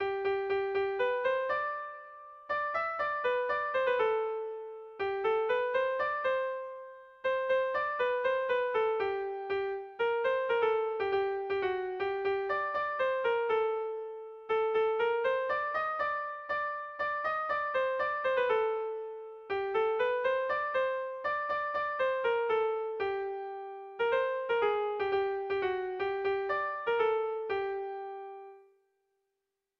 Erromantzea
A1B1DA2B2E